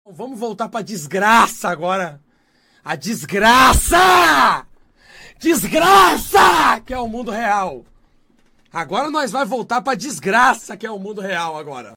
vamo voltar pra desgracaaaaa diiih Meme Sound Effect